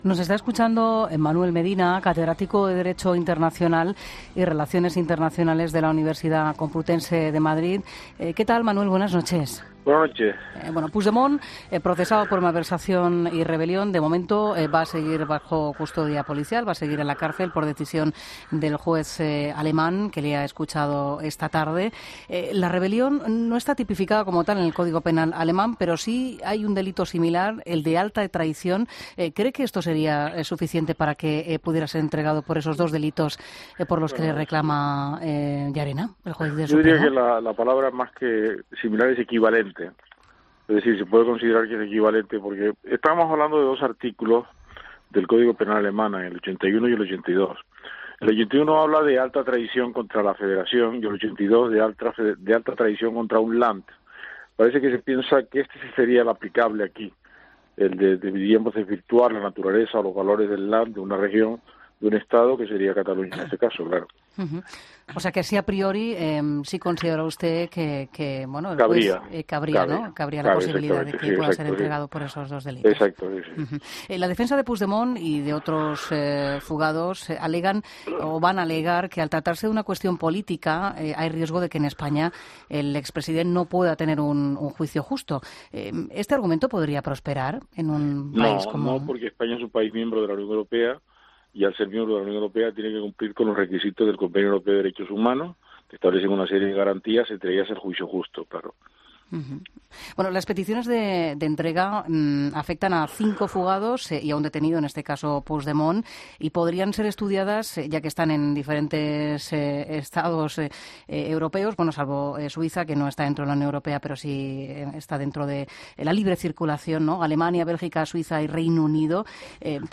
Entrevistas en La Linterna